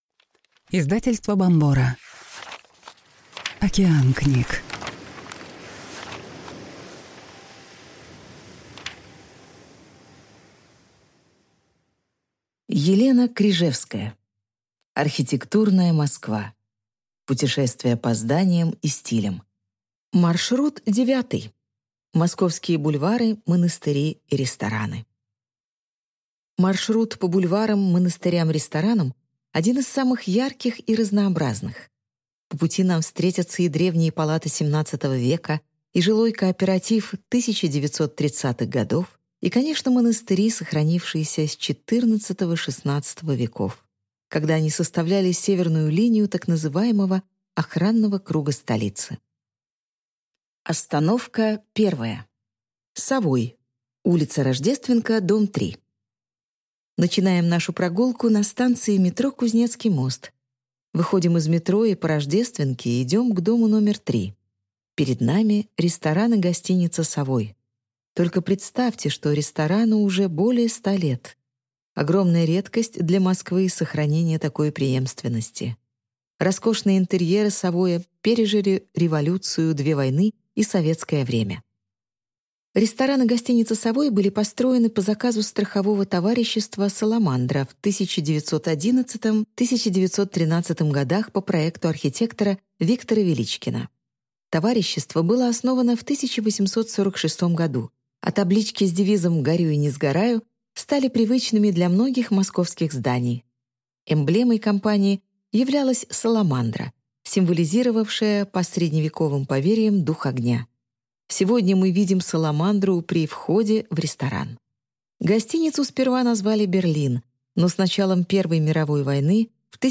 Аудиокнига Московские бульвары, монастыри и рестораны | Библиотека аудиокниг